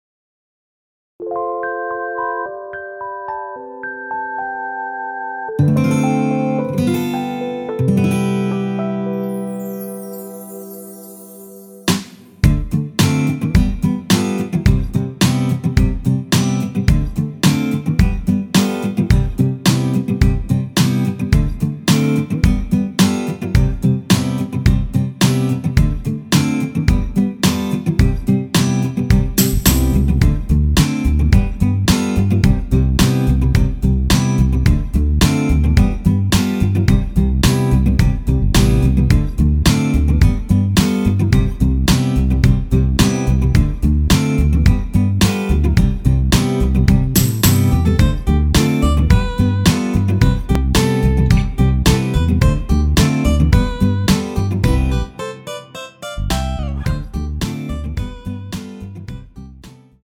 원키에서 (+3)올린 MR입니다.
앞부분30초, 뒷부분30초씩 편집해서 올려 드리고 있습니다.